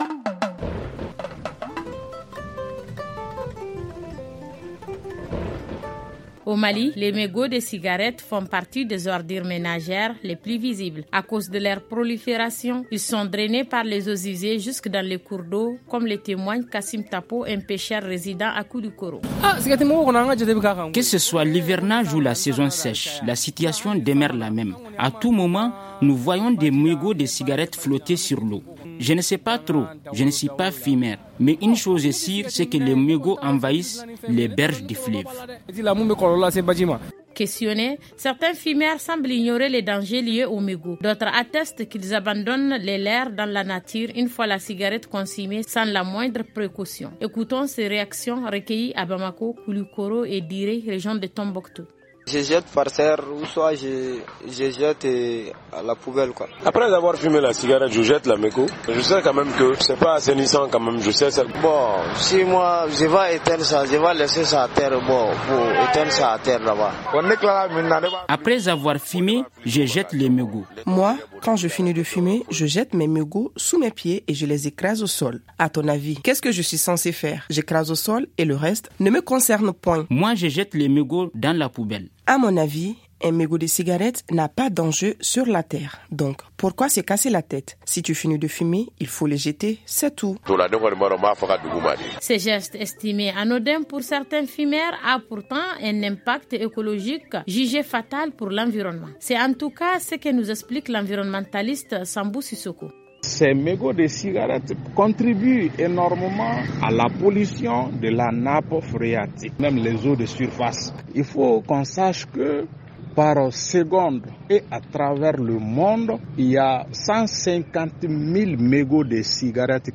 Ci-dessous écoutez le magazine en français: